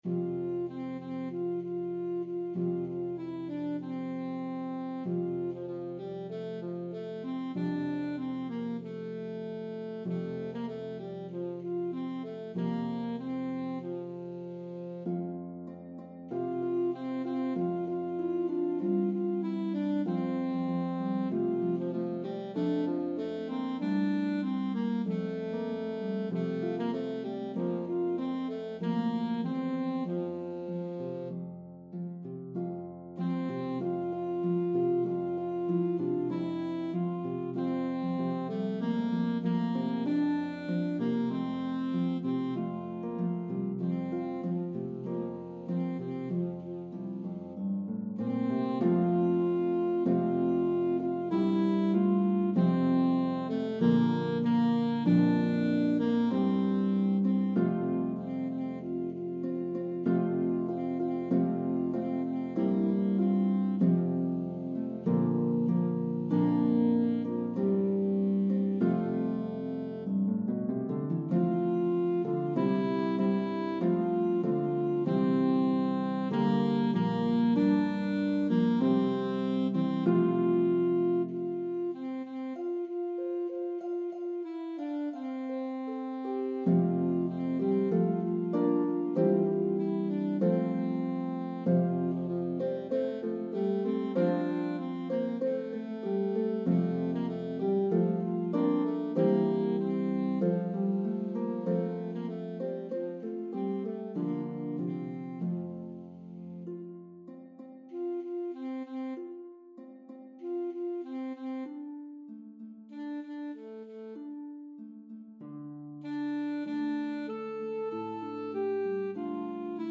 medley of traditional English & Irish Carols